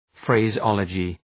Προφορά
{,freızı’ɒlədʒı}